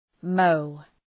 Shkrimi fonetik {məʋ}